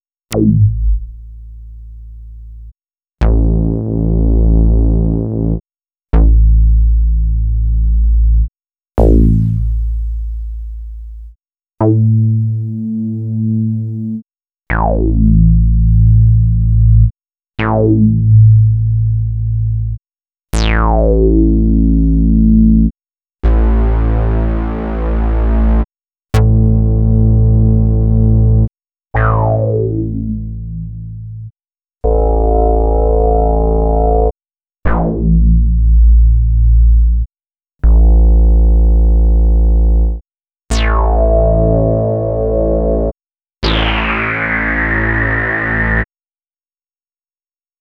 15_FatBass.wav